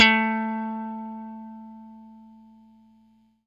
FENDRPLUCKAH.wav